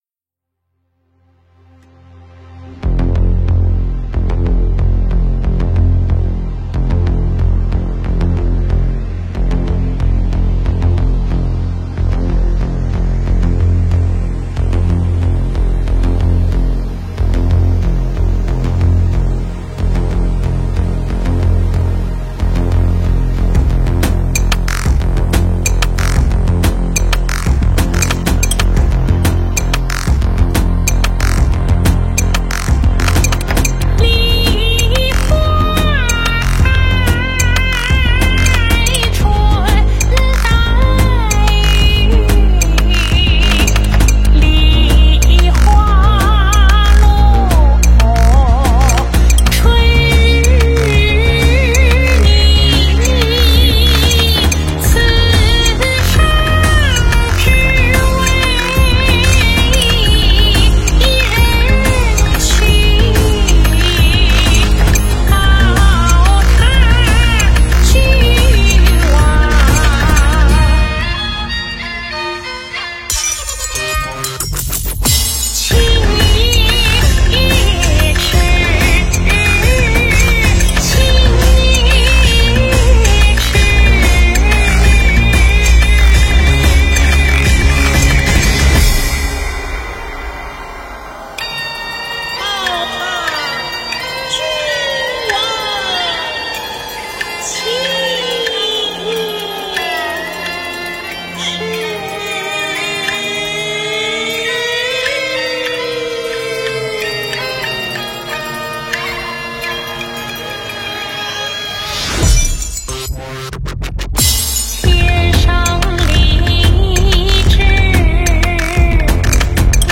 佛音 诵经 佛教音乐 返回列表 上一篇： 花雅禅 下一篇： 恰好 相关文章 慈航--新韵传音 慈航--新韵传音...